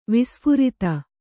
♪ visphurita